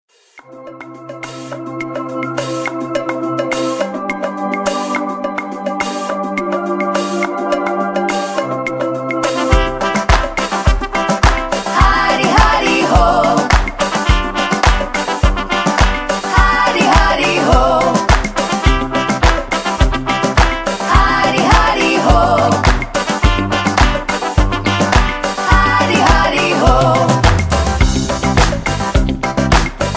Tags: rap , sing-a-long , soul music , backing tracks